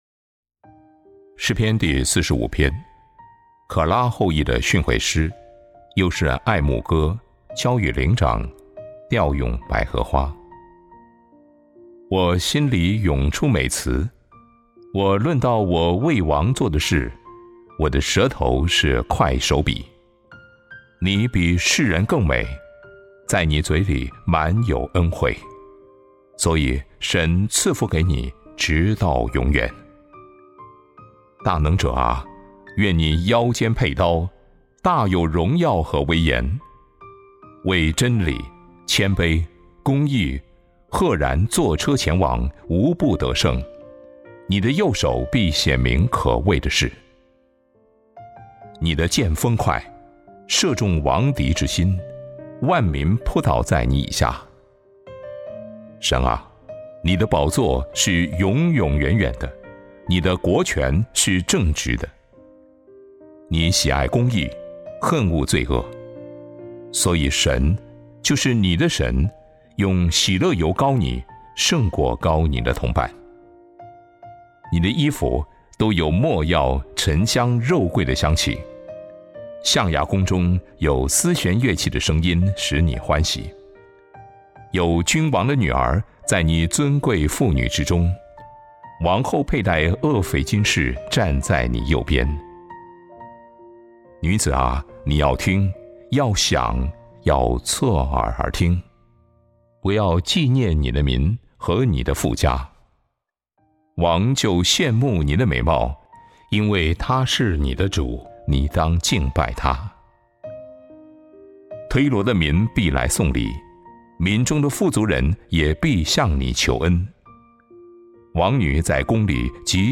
灵修分享